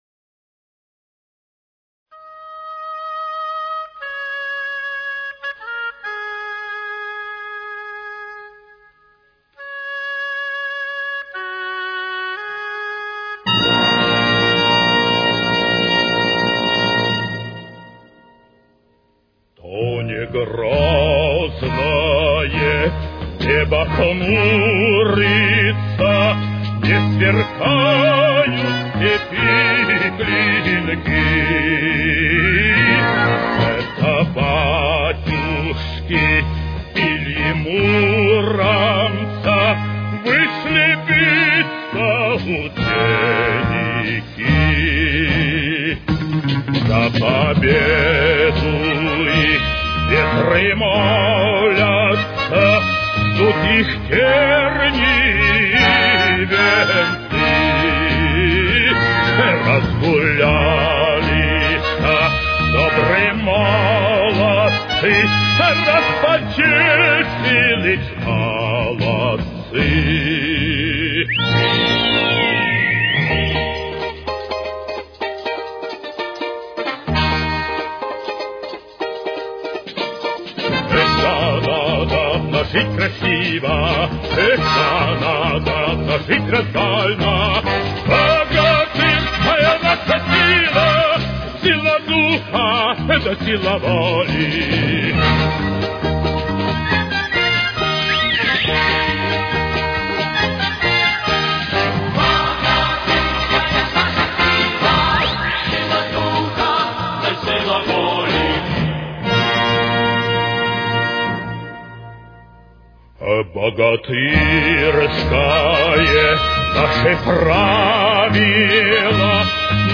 Темп: 77.